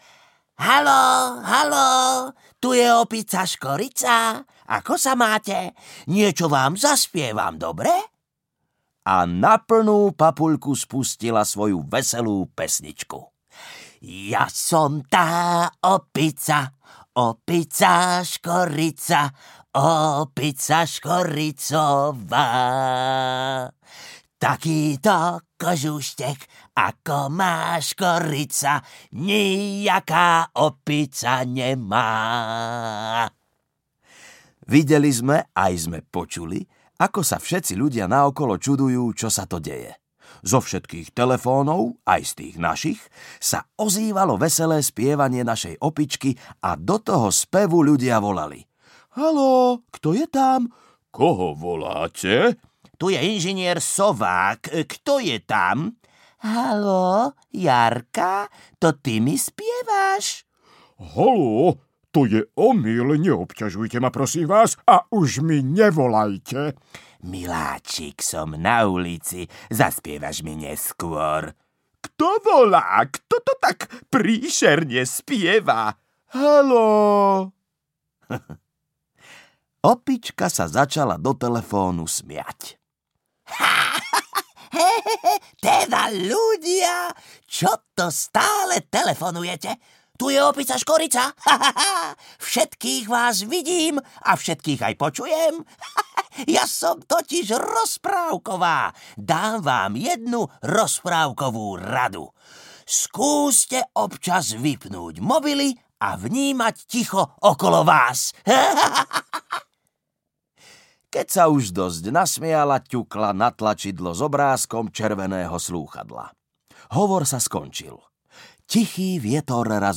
Dobrý deň, opica Škorica audiokniha
Ukázka z knihy